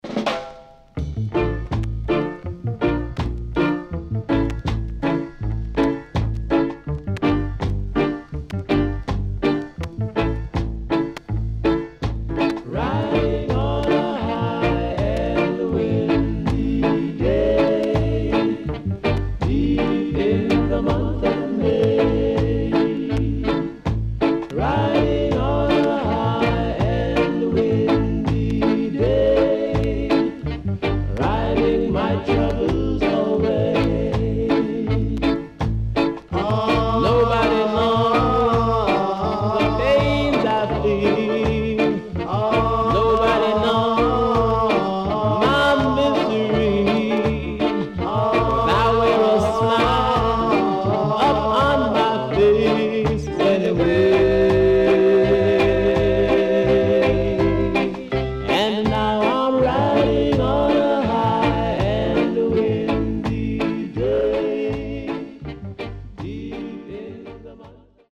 Killer Rocksteady Vocal